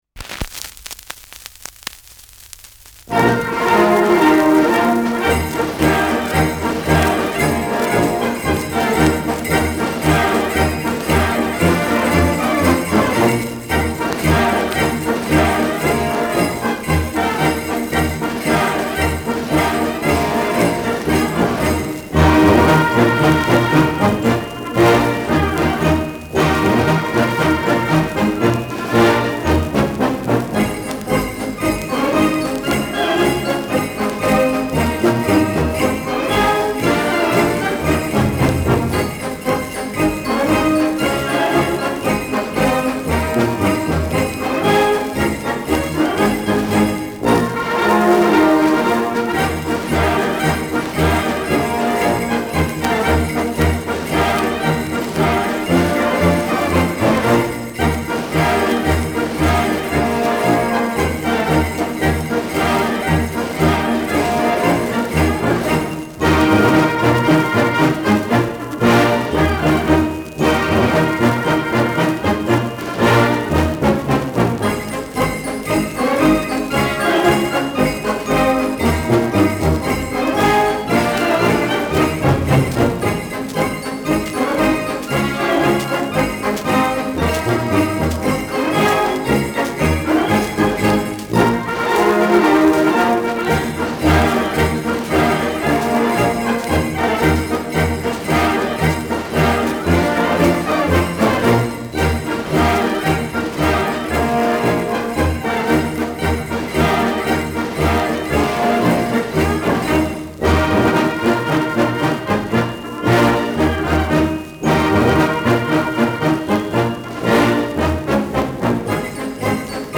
Schellackplatte
Leichtes Nadelgeräusch